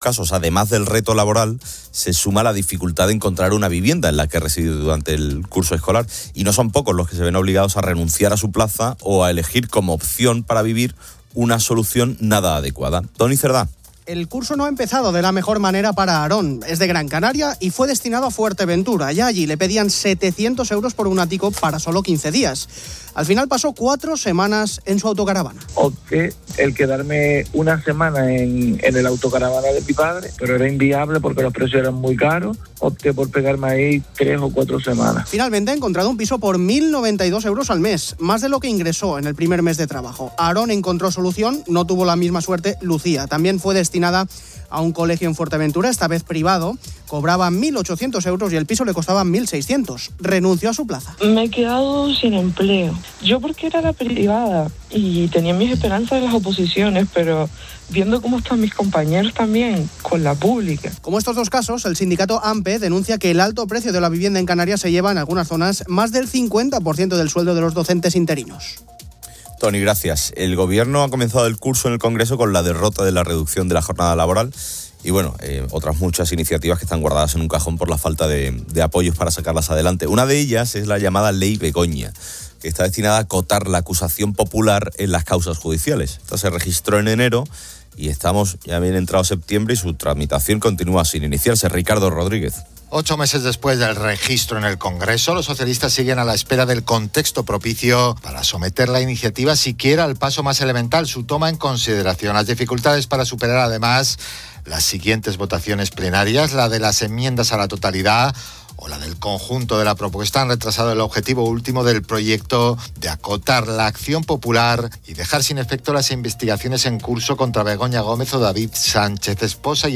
La entrevista central es con Mario Alonso Puig, quien explica que la inteligencia es la capacidad de adaptación y comprensión, más allá del coeficiente intelectual,